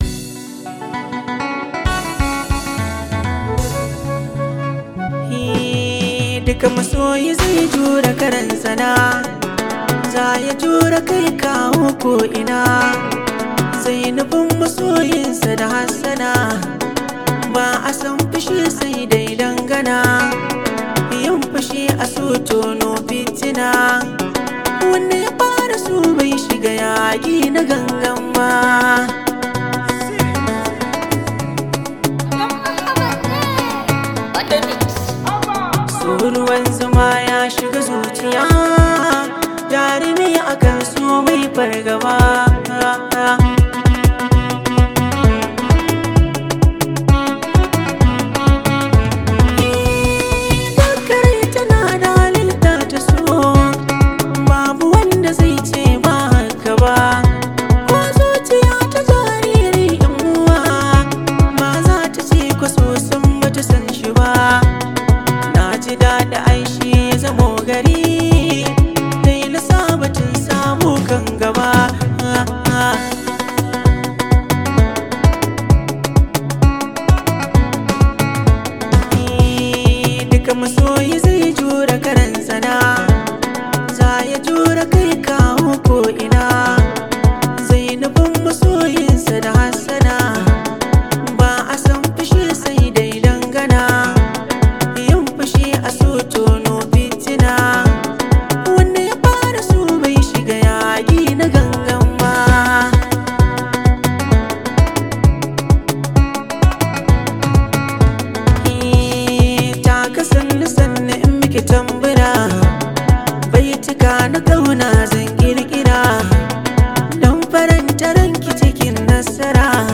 Hausa Musics